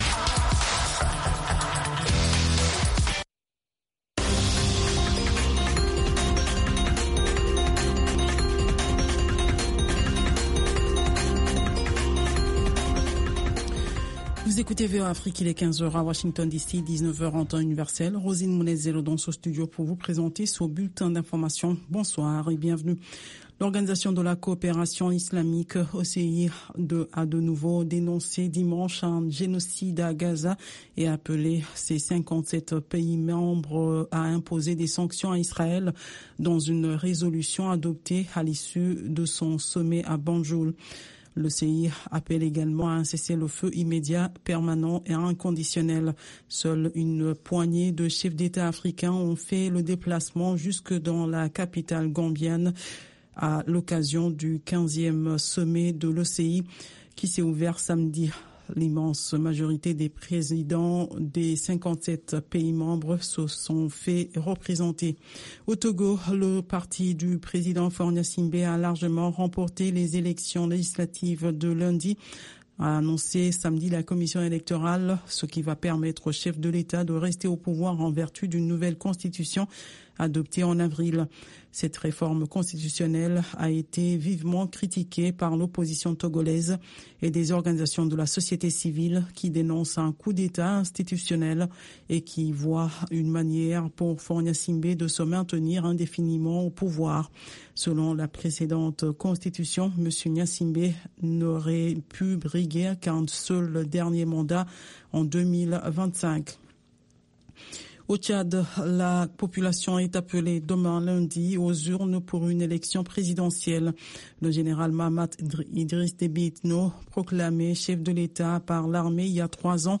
Soul USA - un retour dans les endroits mythiques de la Soul des années 60 et 70.